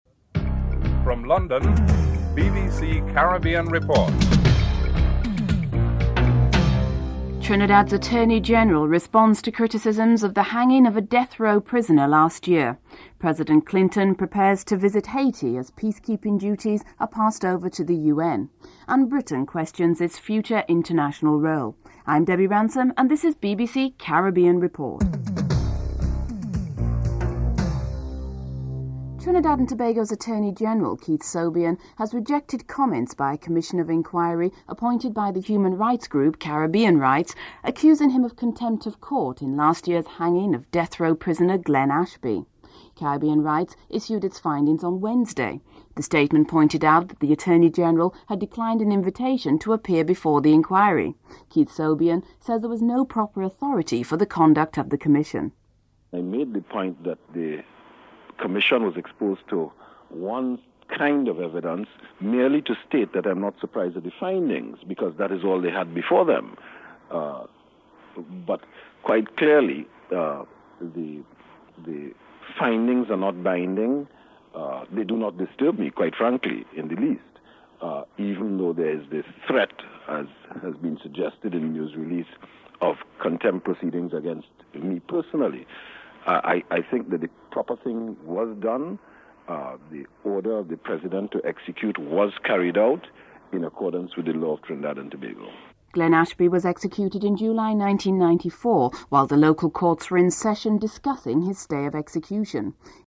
Former Commonwealth Secretary-General Sir Shridath Ramphal comments on how Britain's reputation is viewed from the outside.